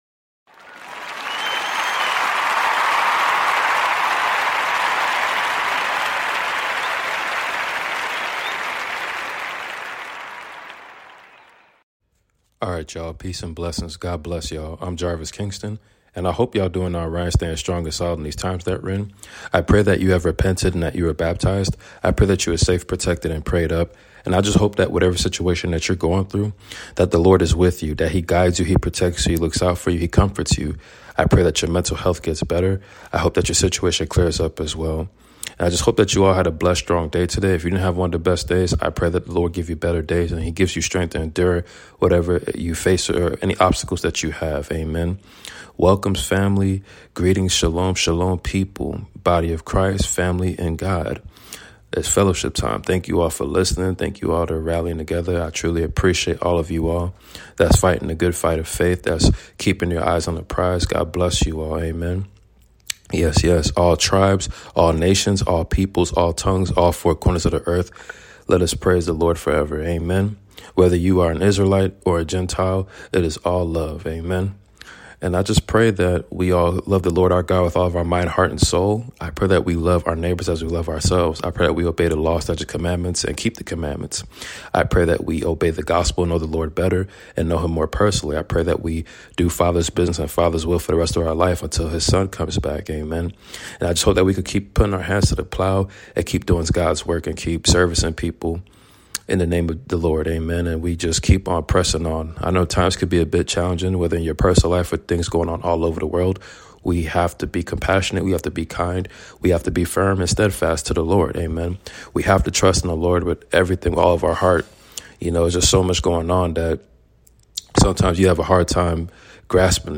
2 Samuel reading ! Stay strong, and encouraged family !!!!!!!